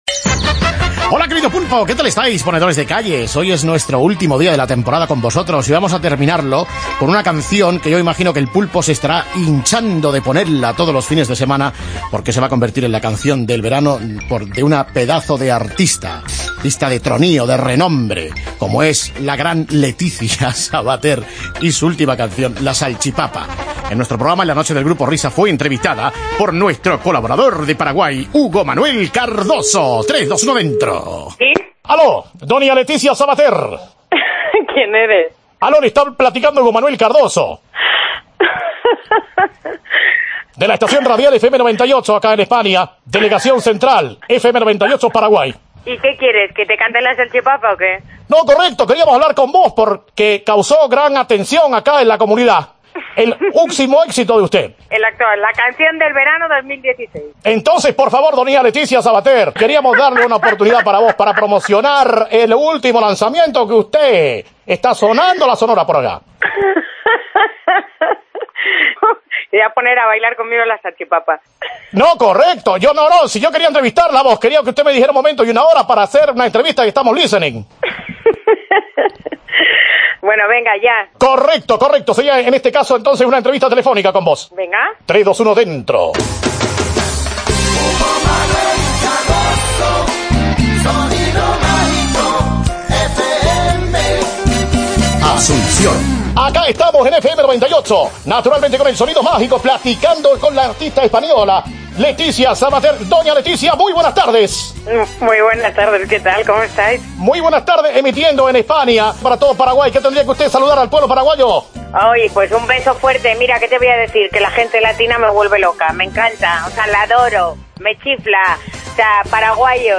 Entrevista a Leticia Sabater por La Salchipapa